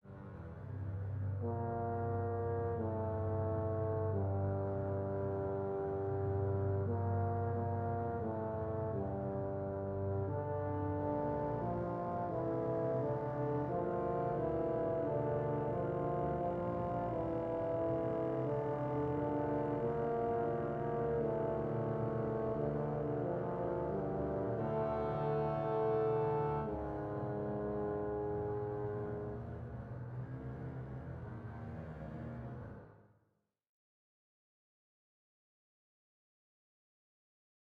Voici donc l’exposé du thème B aux cors et le début de la montée d’orchestre.
dc3a9but-ascension.mp3